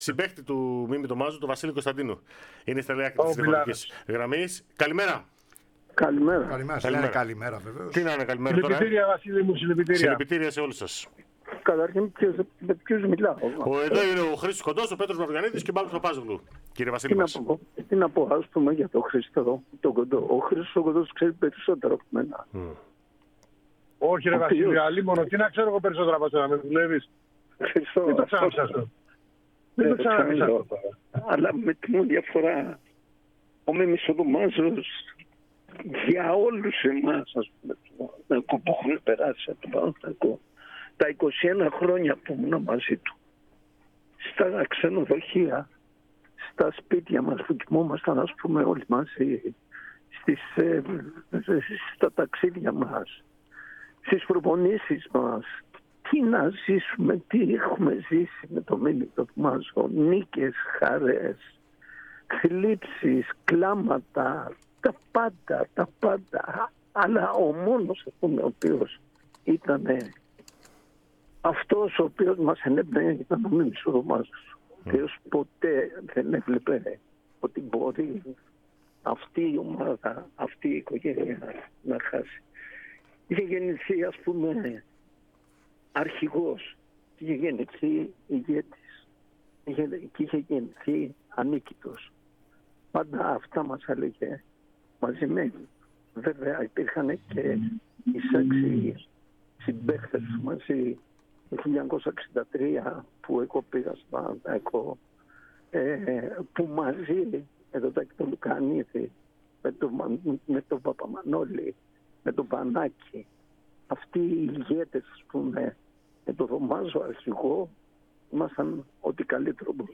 Ο παλαίμαχος ποδοσφαιριστής και συμπαίκτης του Δομάζου, εμφανώς συγκινημένος, προέβη σε δηλώσεις, σχετικές με τον αδικοχαμένο φίλο και συμπαίκτη του στον Παναθηναϊκό.
Ο Βασίλης Κωνσταντίνου, δεν μπορούσε να συγκρατήσει τα δάκρυά του για τον θάνατο του Μίμη Δομάζου, ο οποίος απεβίωσε σήμερα το πρωί σε ηλικία 83 ετών.